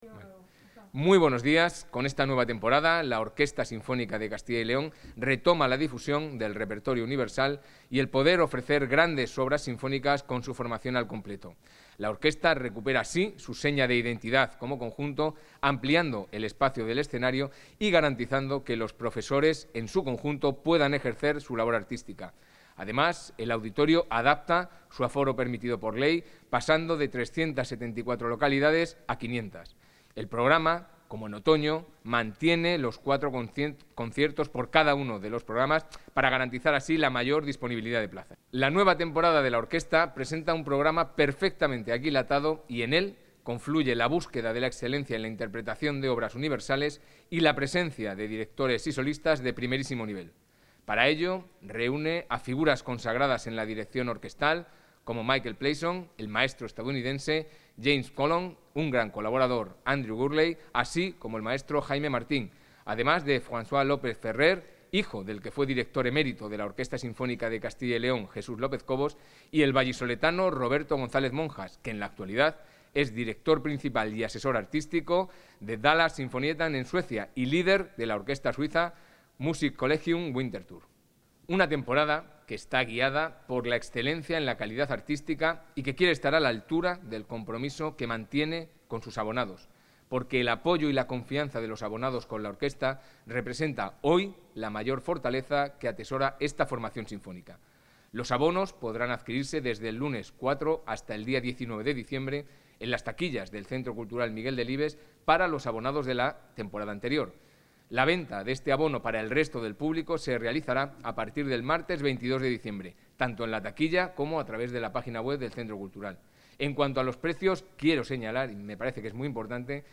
Intervención del consejero de Cultura y Turismo.